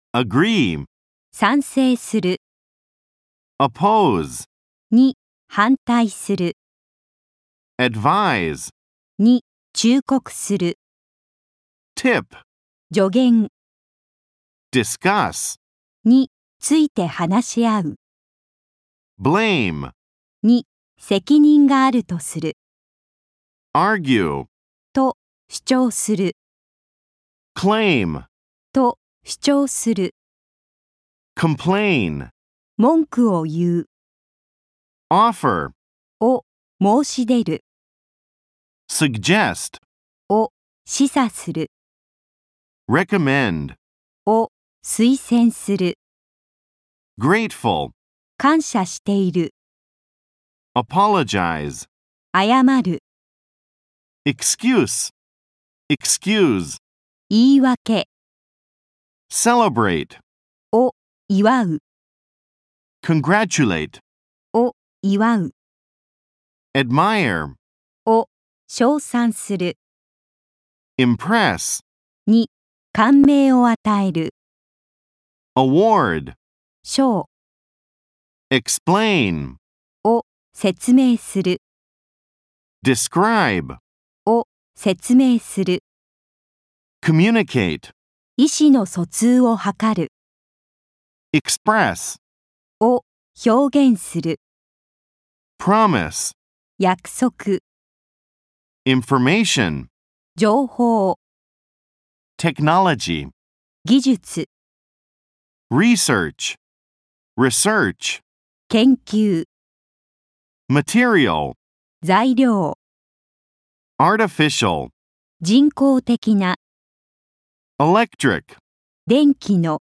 ①音声（単語(英→日) Part 1 Week 1）